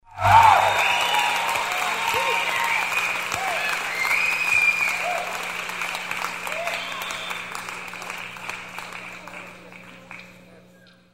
APLAUSOE FECTOS DE SONIDO APLAUSOS Y ALEGRIA
Ambient sound effects
aplausoe_fectos_de_sonido_aplausos_y_alegria.mp3